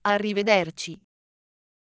I just had to put in my credit card, the machine spit it back with a receipt, and said